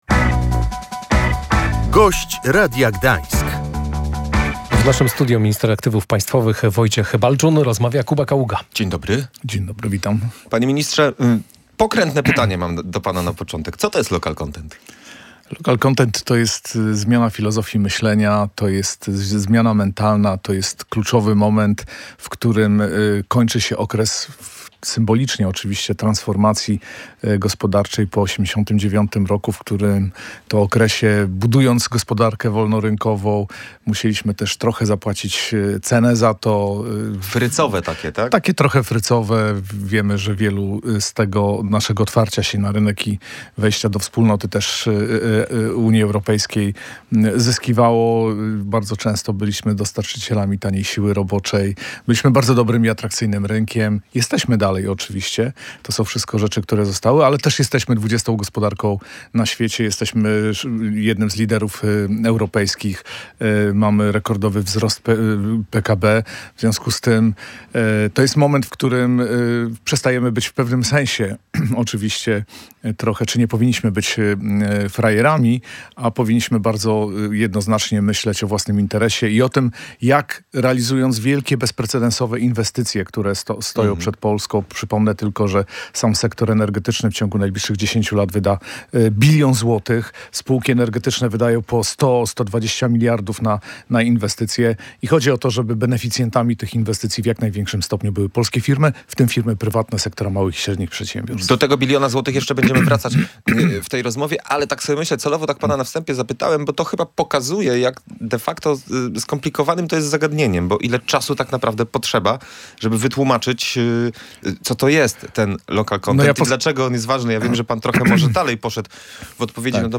To radykalna zmiana sposobu myślenia – mówił w Radiu Gdańsk minister aktywów państwowych Wojciech Balczun.